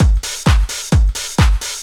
Index of /90_sSampleCDs/Ueberschall - Techno Trance Essentials/02-29 DRUMLOOPS/TE06-09.LOOP-TRANCE/TE08.LOOP-TRANCE3